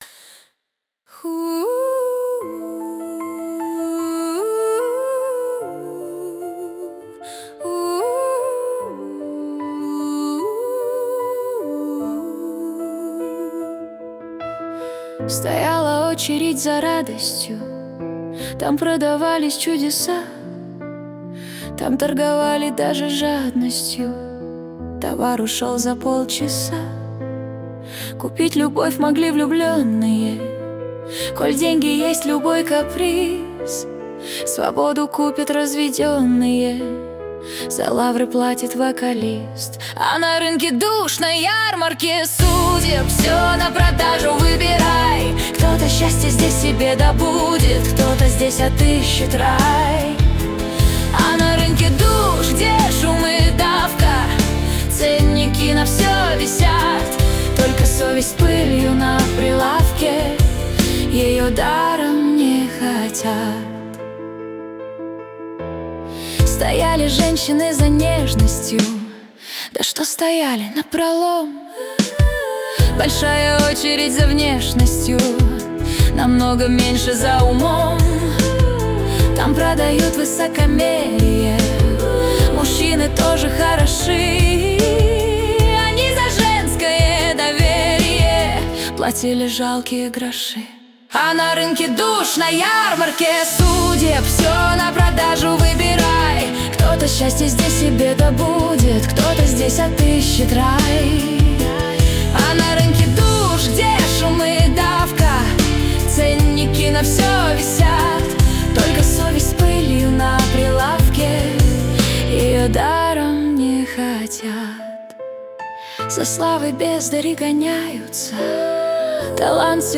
Жанр: Pop | Год: 2026